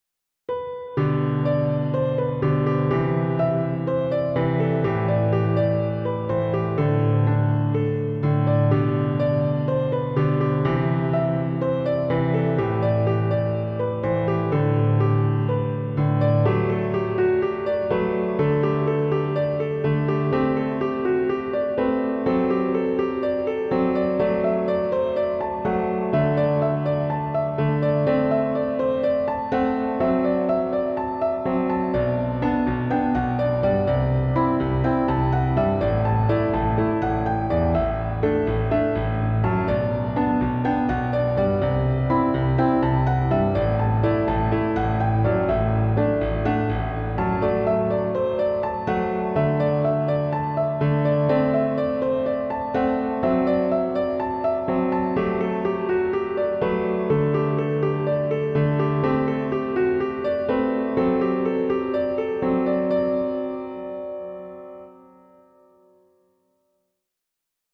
PIANO Q-S (31)